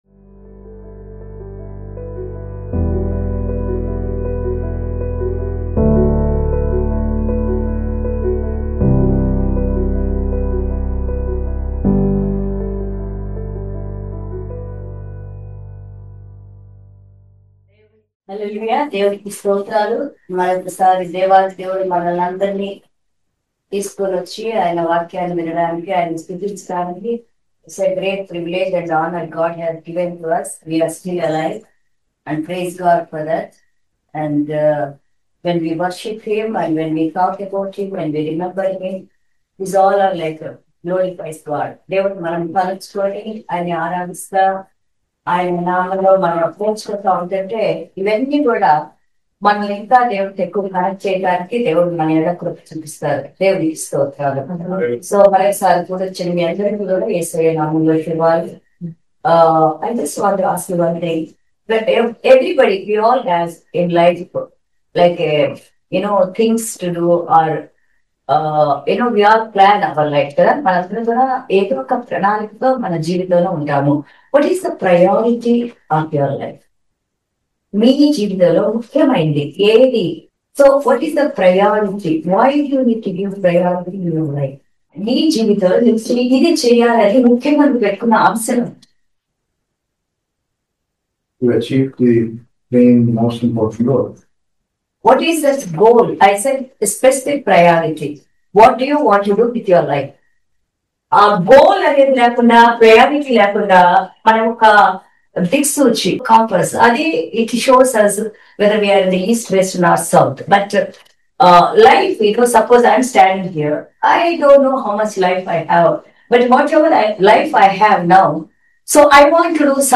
Sermon 10.09.2025